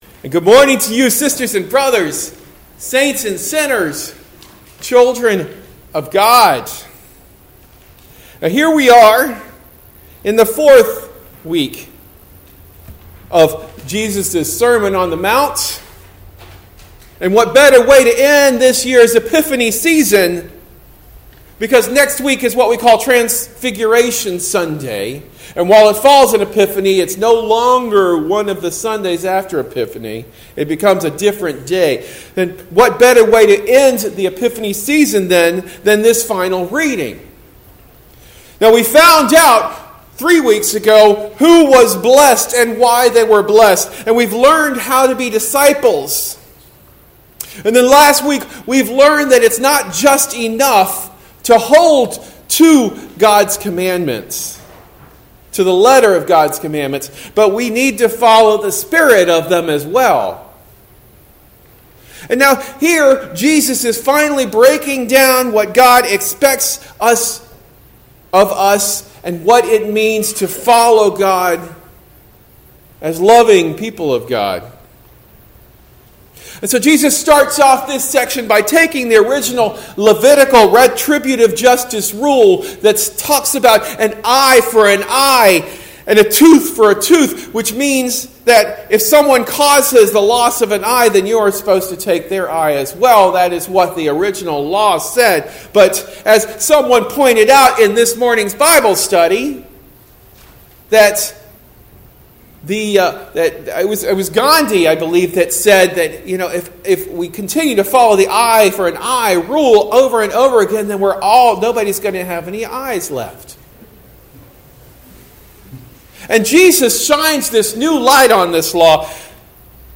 Sermon delivered at Lutheran Church of the Cross in Berkeley.